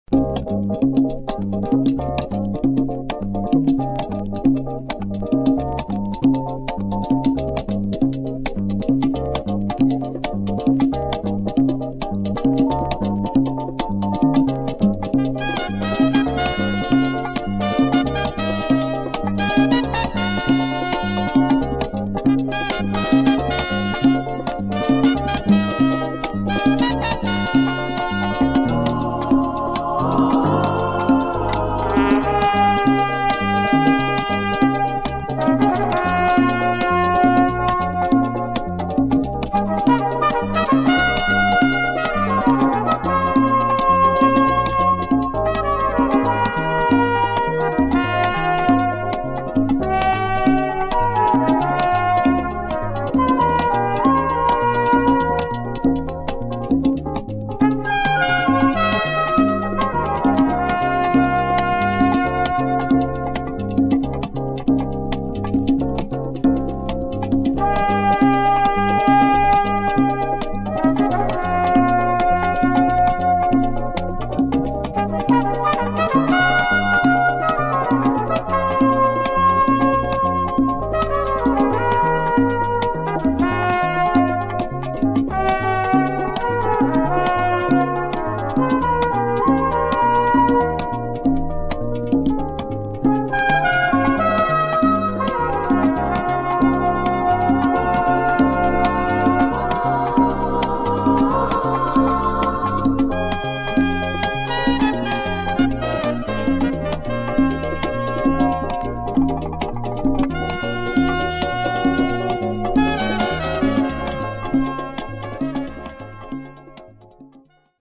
descarga jazzy